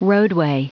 Prononciation du mot roadway en anglais (fichier audio)
Prononciation du mot : roadway